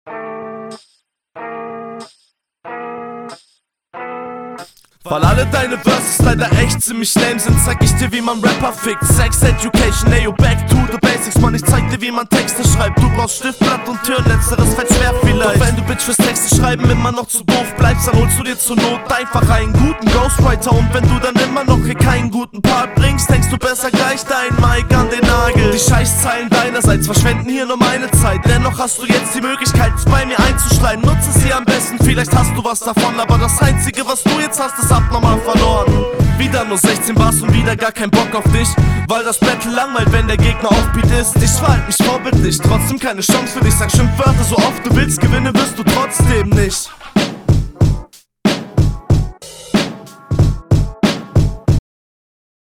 Sound um einiges besser als bei deinem Kontrahenten.
Stimmeinsatz besser.